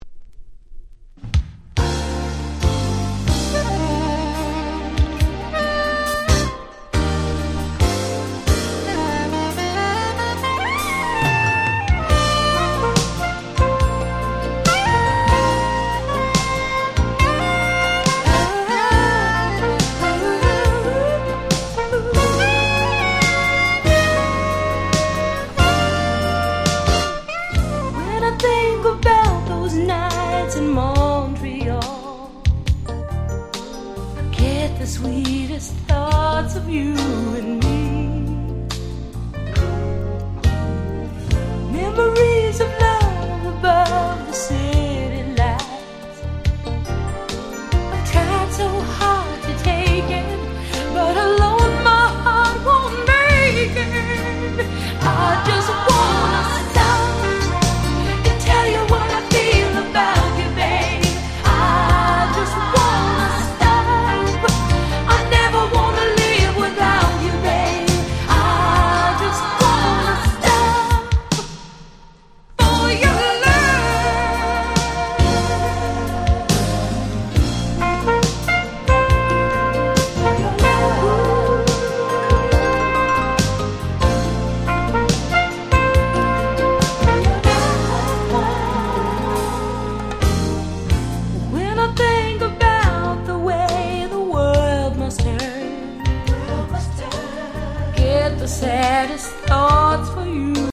88' Very Nice Mid / Slow R&B !!
両面共に最高のSlow / Mid。
スロウジャム 80's バラード